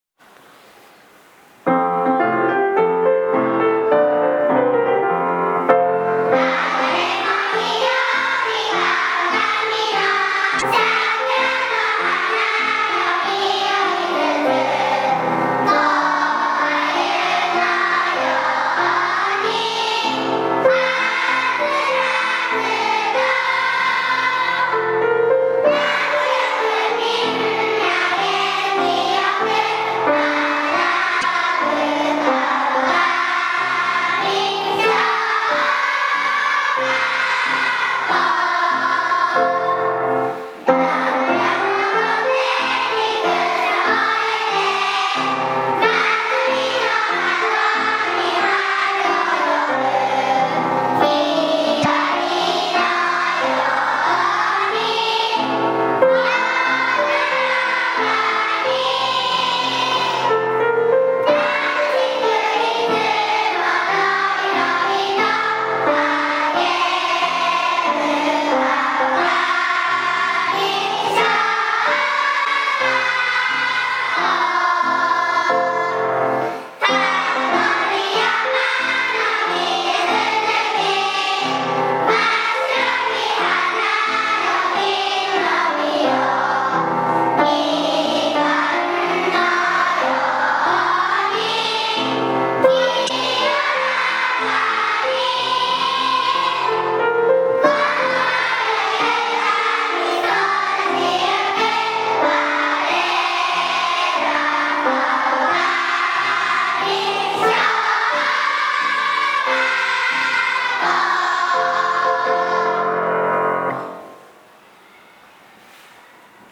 川上小校歌１年.mp3